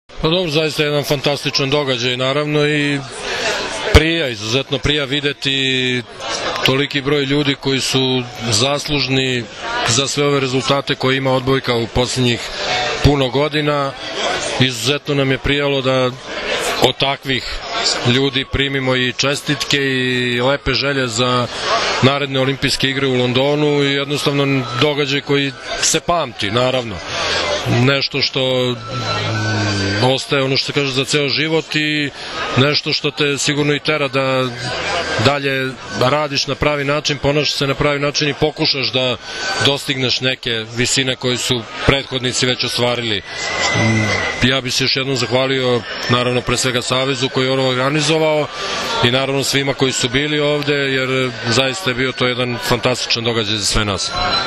Odbojkaški savez Srbije je večeras u beogradskom hotelu „M“ priredio svečanost pod nazivom „Olimpijski kontinutitet“ povodom plasmana ženske i muške seniorske reprezentacije na Olimpijske igre u Londonu.
IZJAVA